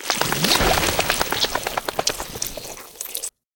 shake.ogg